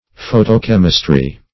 Photochemistry \Pho`to*chem"is*try\, n. [Photo- + chemistry.]